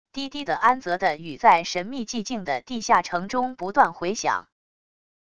低低的安泽地语在神秘寂静的地下城中不断回响wav音频